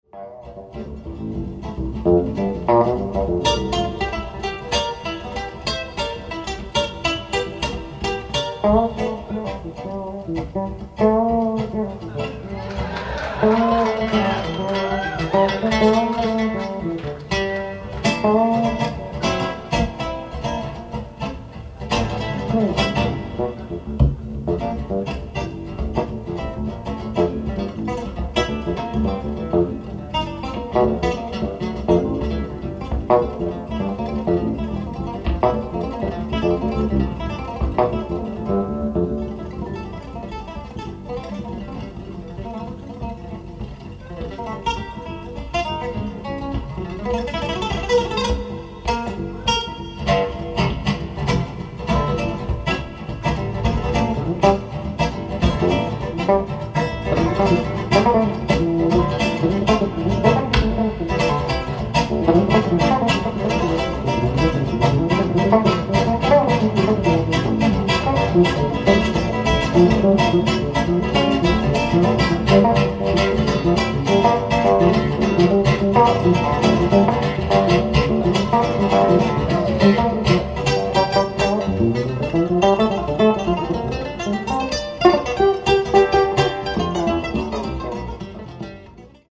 ライブ・アット・ケンブリッジ、マサチューセッツ 08/10/1987
※試聴用に実際より音質を落としています。